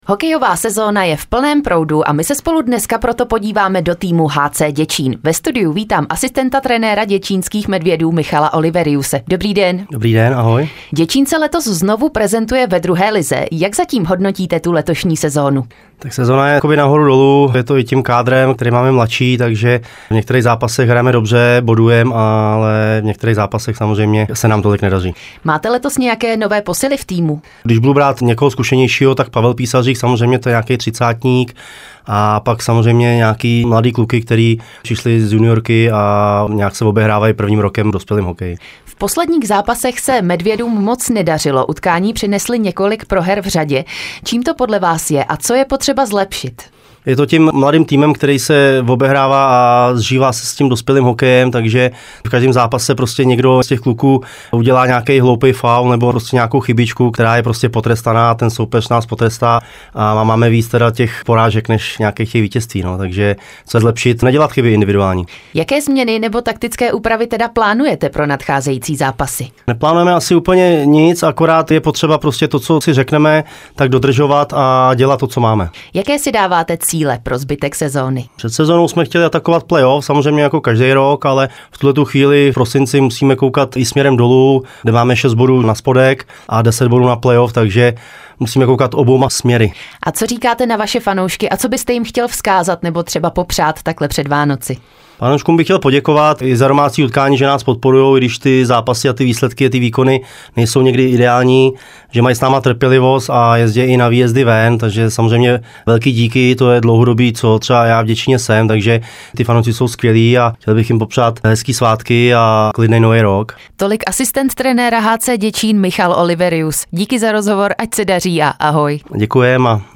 ROZHOVOR PRO HITRÁDIO CONTACT